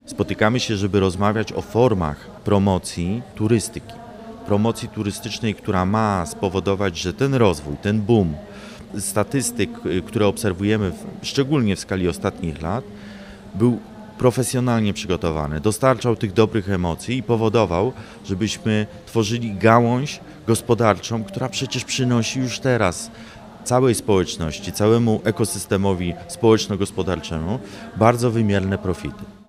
Z kolei Jakub Mazur – wiceprezydent Wrocławia, otwierając Forum wskazał na bardzo ważną rolę turystyki w gospodarczym rozwoju wielu obszarów: